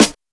Him_Snr.wav